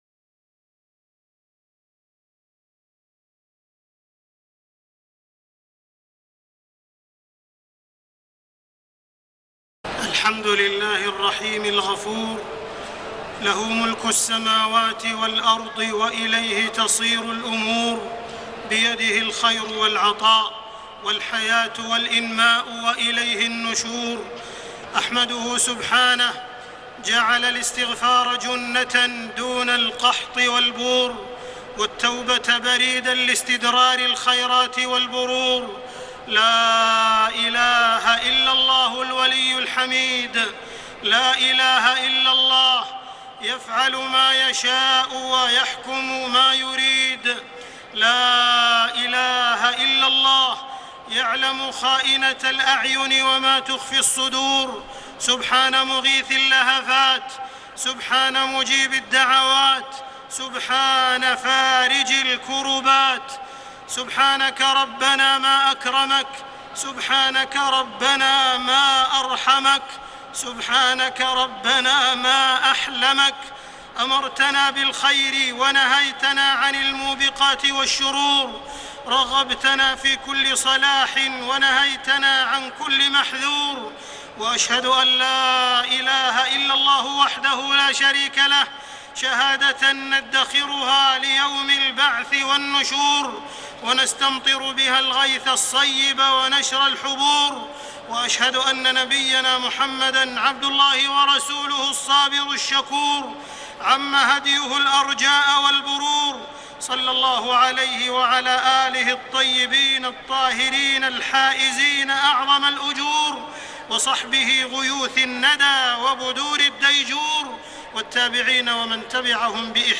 خطبة الاستسقاء 7 محرم 1432هـ > خطب الاستسقاء 🕋 > المزيد - تلاوات الحرمين